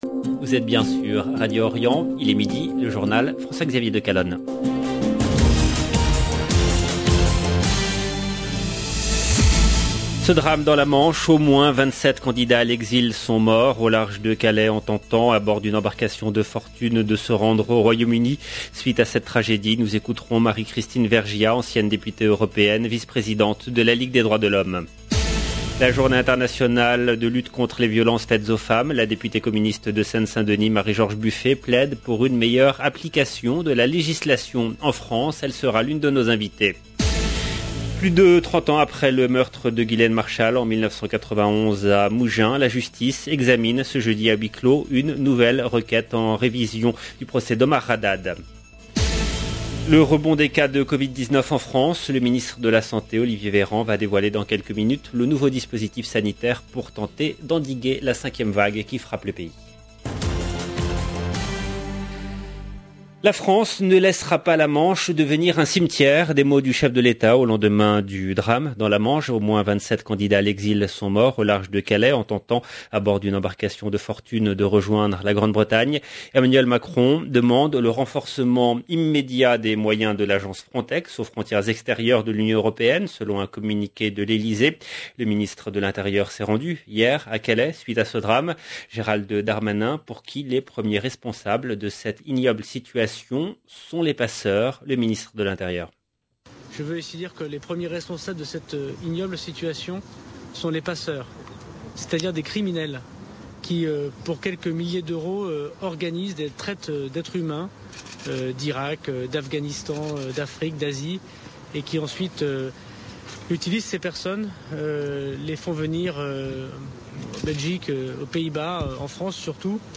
Suite à cette tragédie, nous écouterons Marie-Christine Vergiat, ancienne députée européenne, vice-présidente de la Ligue des droits de l’Homme.
Elle sera l’une de nos invitées.